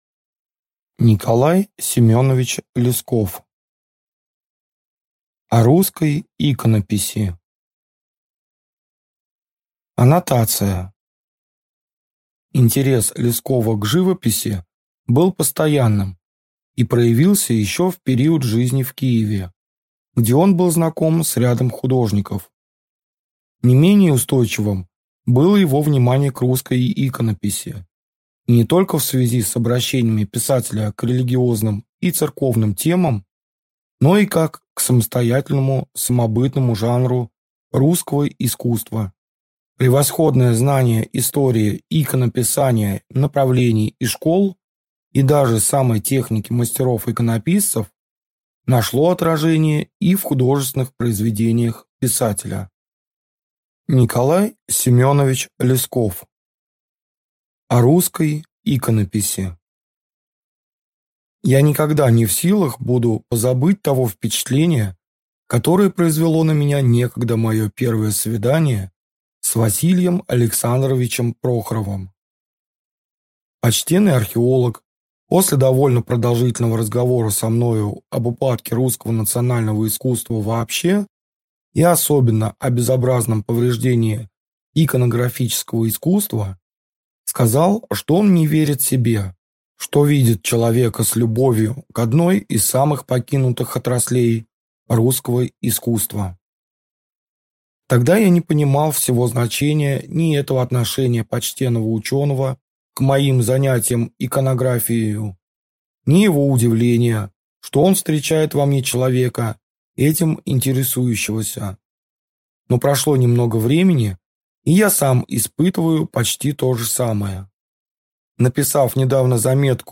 Аудиокнига О русской иконописи | Библиотека аудиокниг